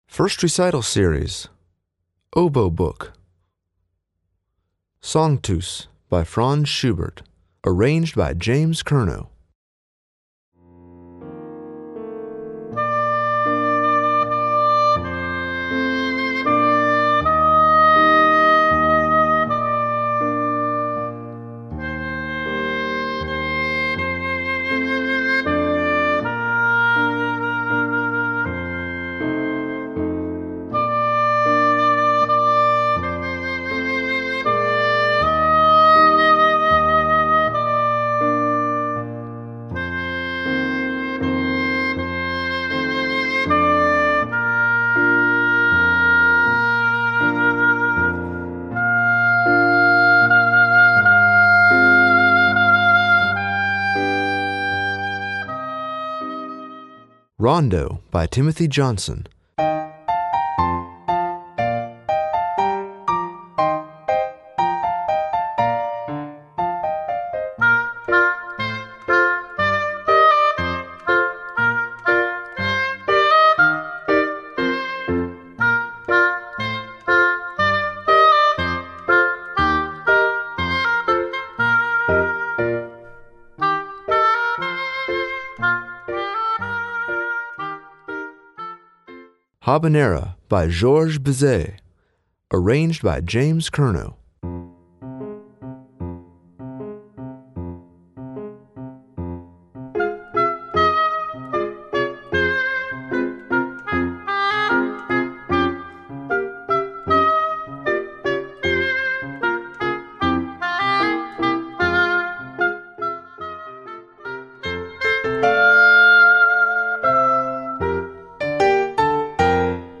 Voicing: Piano Accompaniment